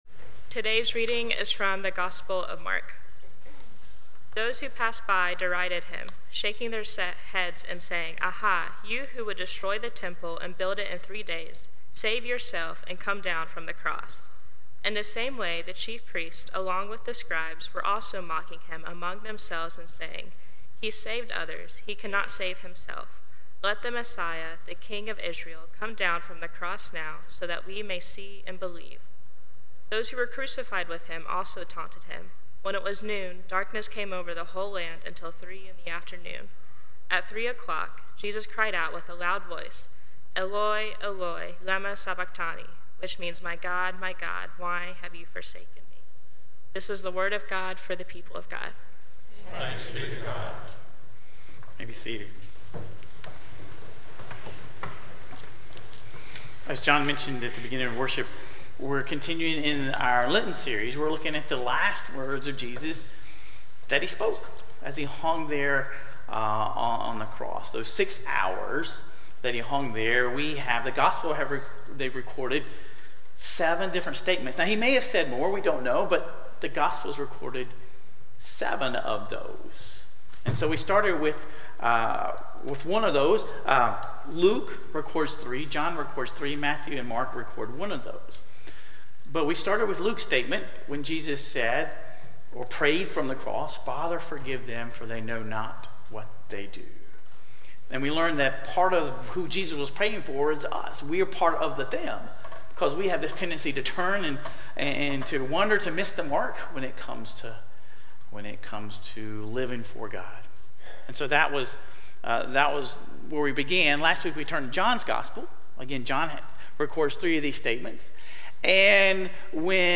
Sermon Reflections: When have you experienced a "dark night" or feeling of abandonment in your own spiritual journey?